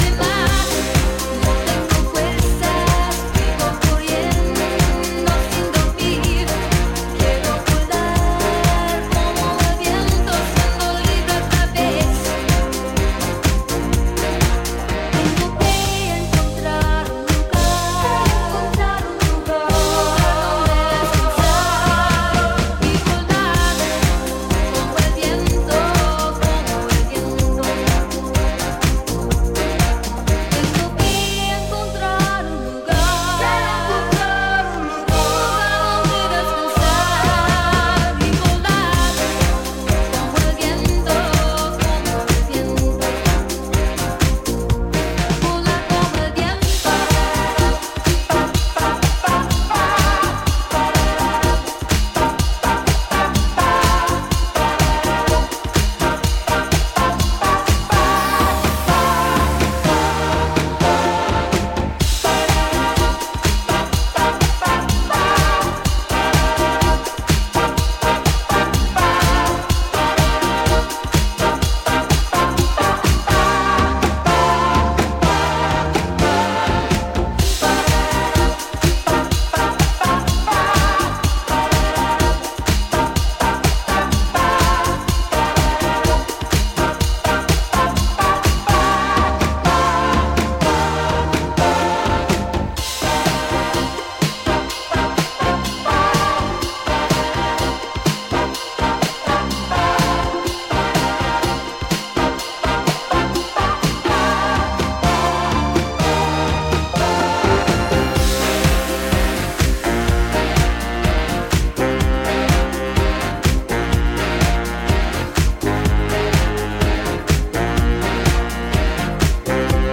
distinctly underground energy